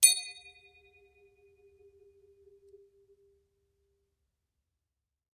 Triangle Medium Strike Spins 2